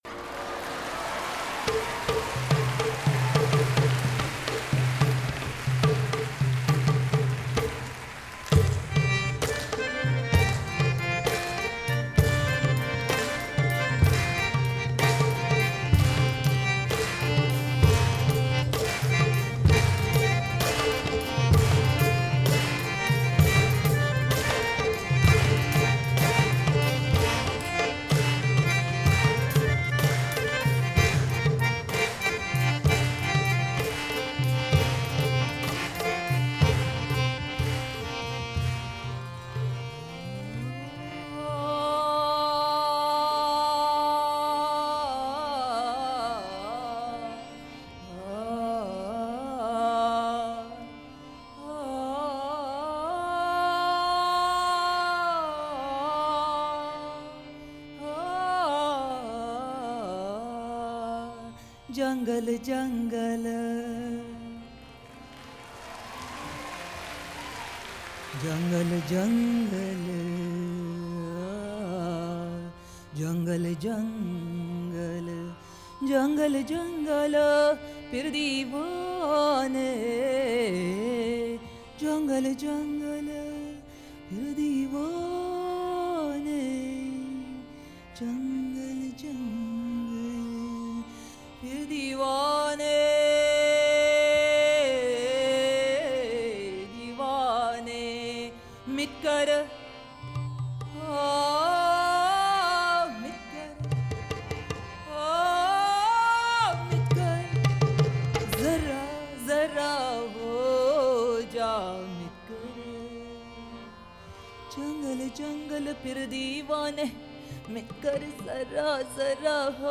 A few days before the show she gave me an assignment re the flute’s role later in the song.